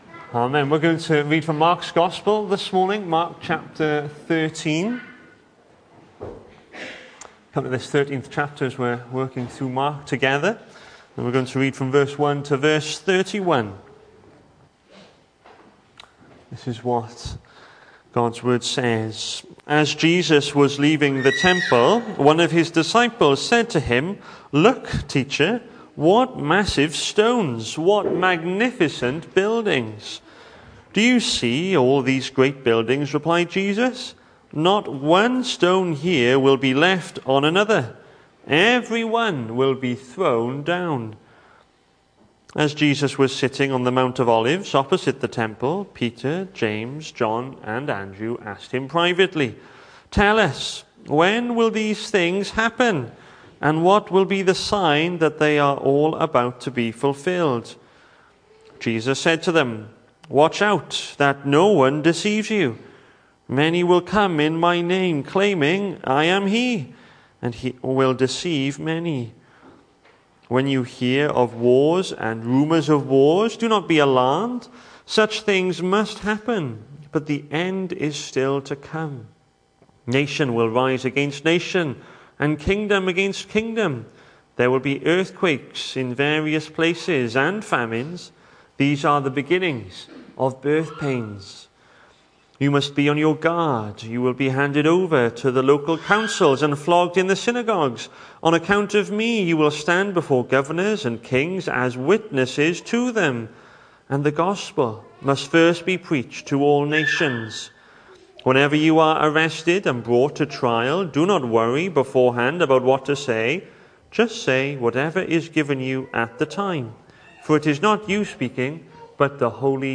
Hello and welcome to Bethel Evangelical Church in Gorseinon and thank you for checking out this weeks sermon recordings.
The 14th of September saw us host our Sunday morning service from the church building, with a livestream available via Facebook.